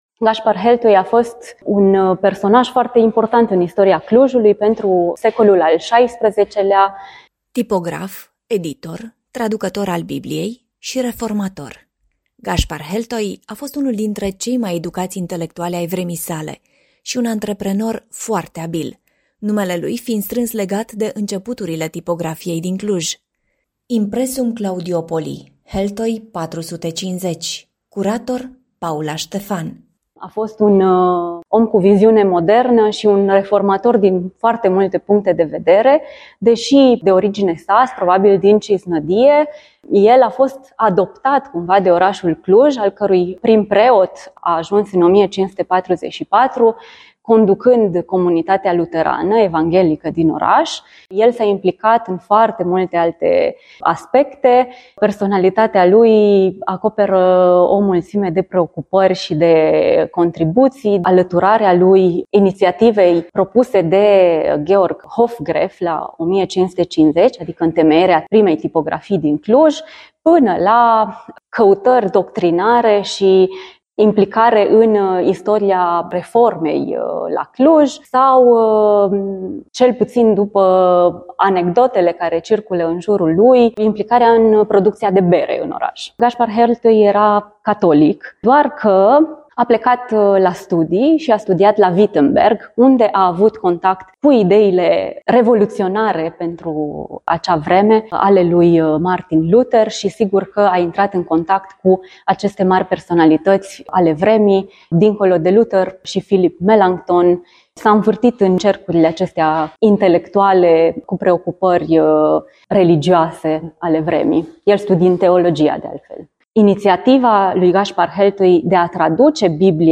Ascultați mai jos, în format audio, un reportaj realizat la Biblioteca Centrală Universitară ”Lucian Blaga”: